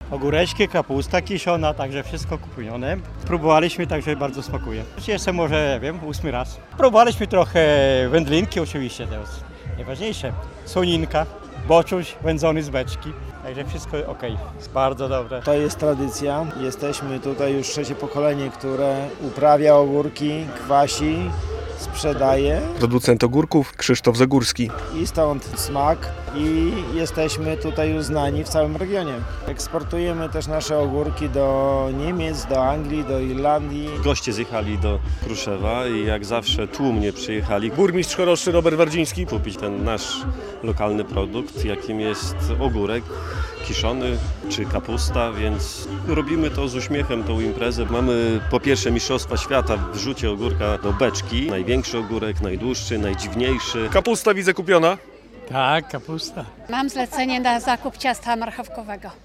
Mnóstwo atrakcji było na 24. Ogólnopolskim Dniu Ogórka w Kruszewie - relacja